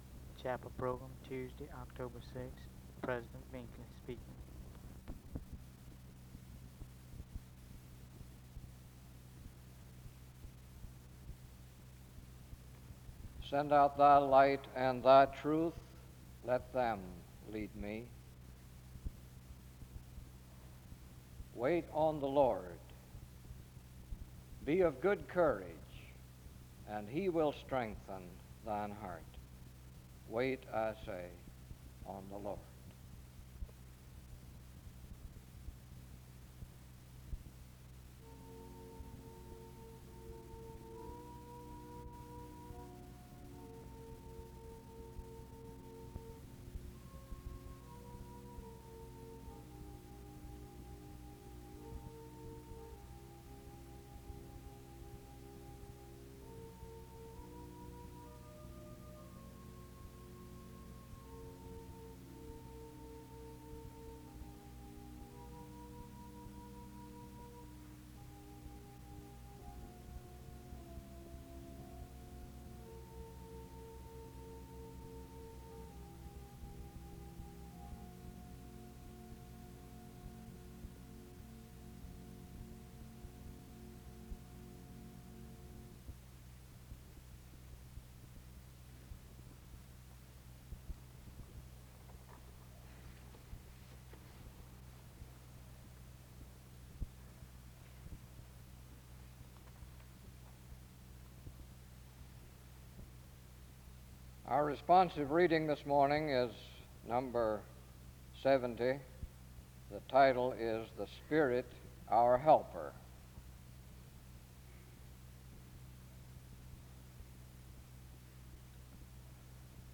Music plays from 6:19-11:48.
Wake Forest (N.C.)